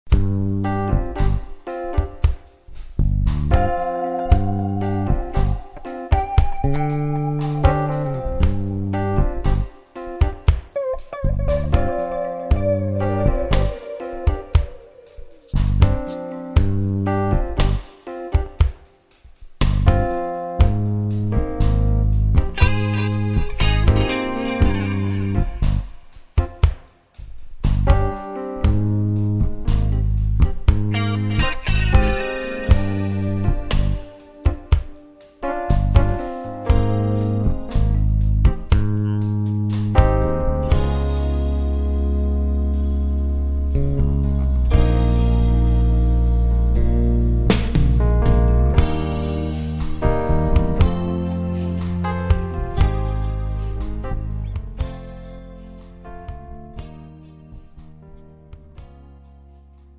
(뮤지컬) MR 반주입니다.